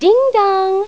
A small collection of sounds from 'Sister Sister'.
All voices by Tia and Tamera.
dingdong.wav